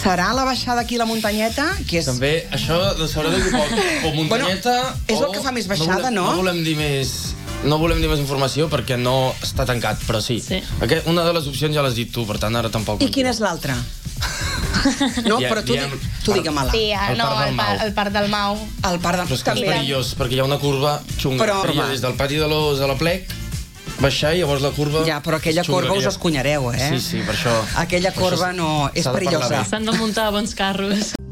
Aquest dilluns, una representació de l’associació ha passat pel matinal de RCT per explicar els seus projectes entre els que hi ha crear una marca que els identifiqui.